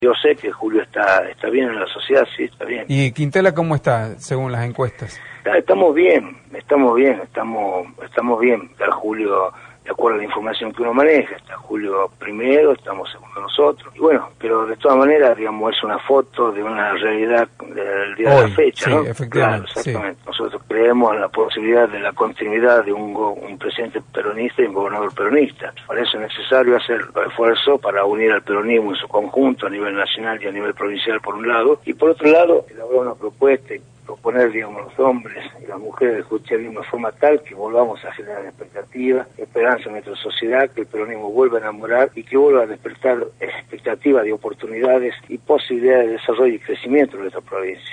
ricardo-quintela-por-radio-la-red.mp3